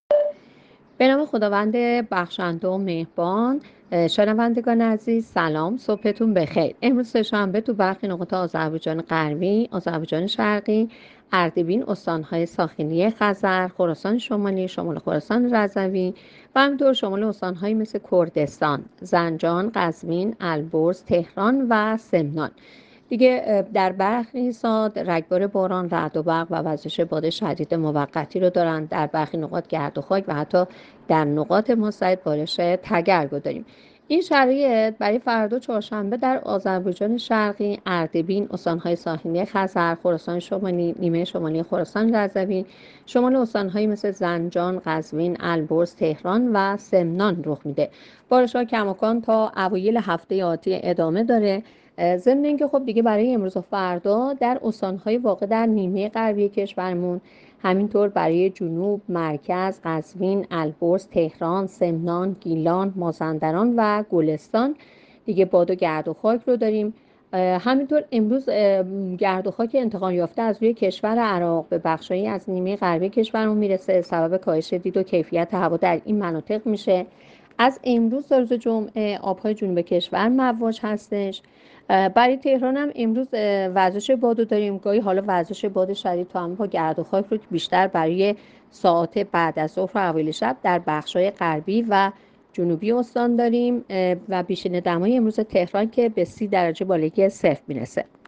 گزارش رادیو اینترنتی پایگاه‌ خبری از آخرین وضعیت آب‌وهوای شانزدهم اردیبهشت؛